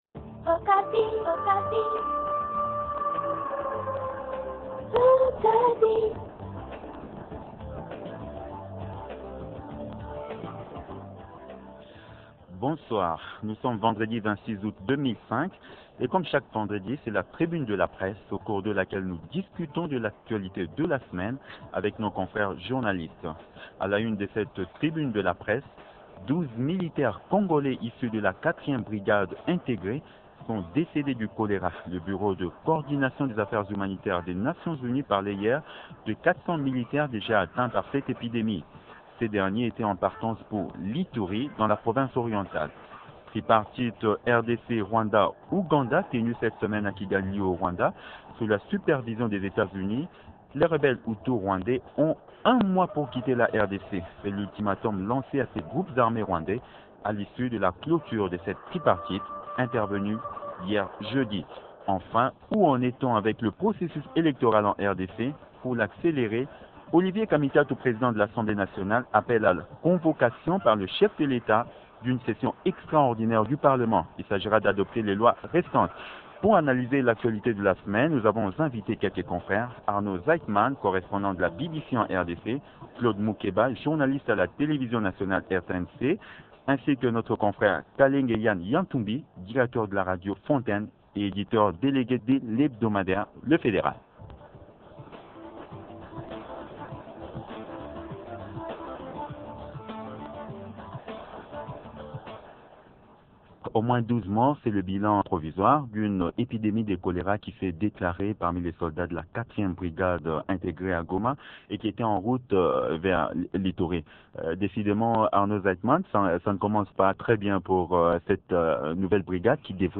Trois thèmes à débattre ce soir